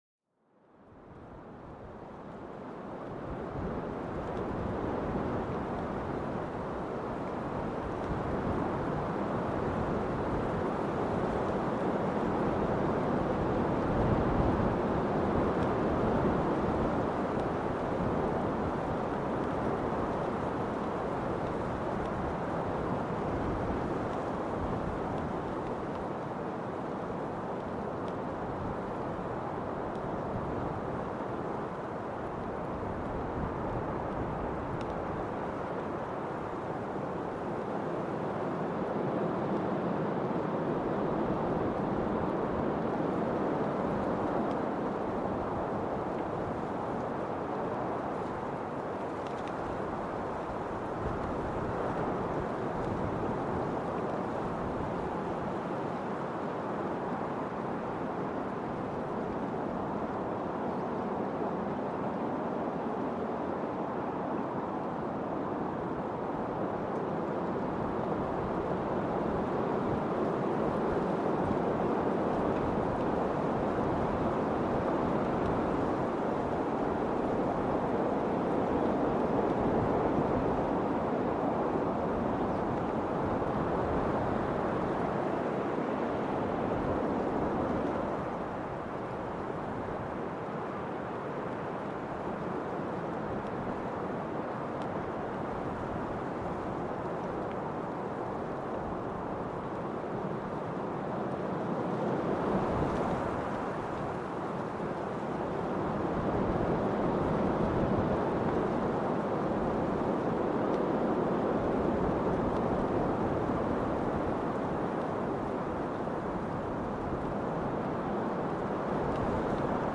Sumérgete en el corazón de una tormenta de viento, donde las ráfagas poderosas te envuelven en una manta de sonido puro e intenso. Déjate llevar por esta sinfonía natural, un viaje sonoro que estimula la mente mientras calma el alma.